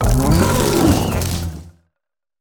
Grito de Wo-Chien.ogg
Grito_de_Wo-Chien.ogg.mp3